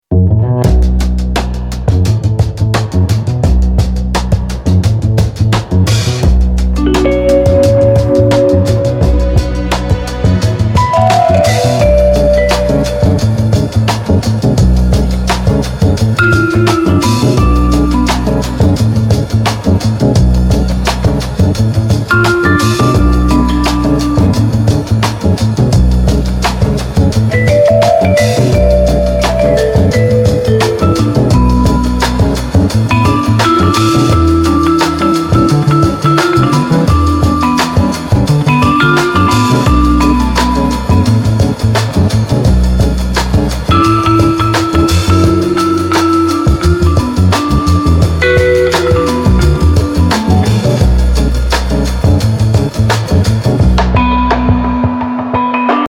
BEST OF DRUM BREAKS 30
It includes a drum break loop sound that can be used for beat making. There are two types in one track.
deep-jazzy-breaks-03-s-1.mp3